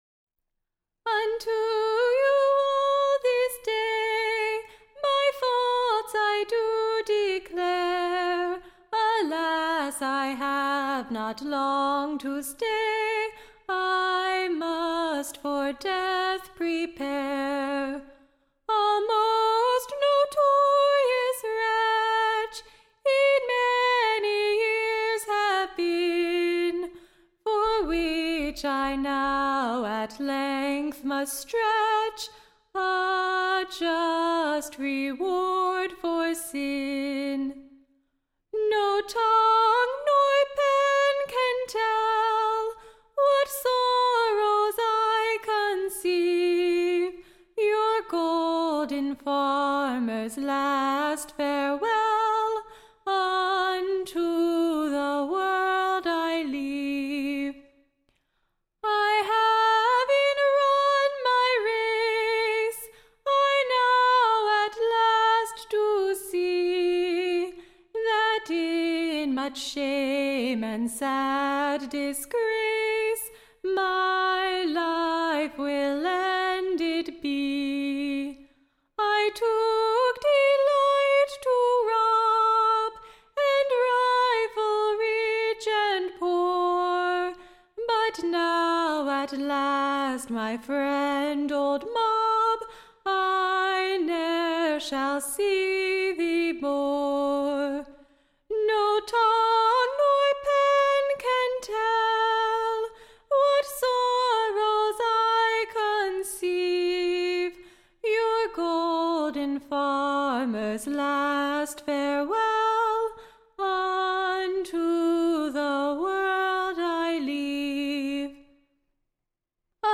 Execution Ballads